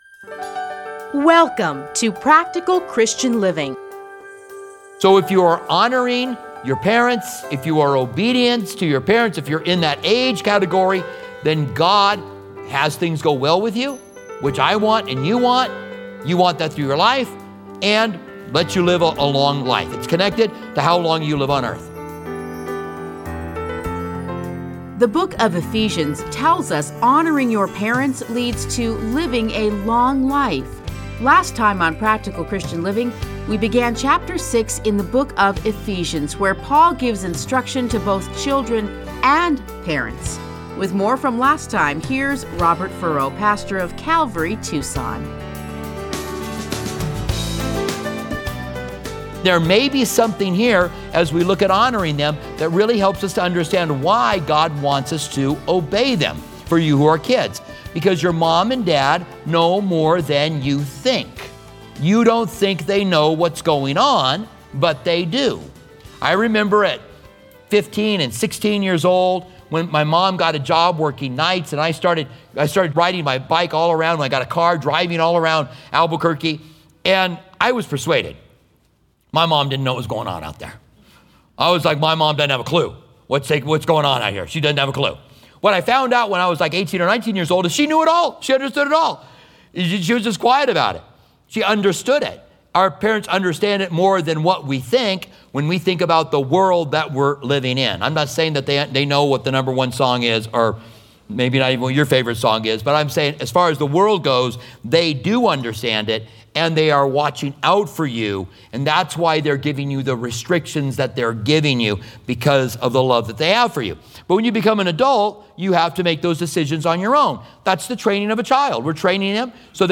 Listen to a teaching from Ephesians 6:1-4.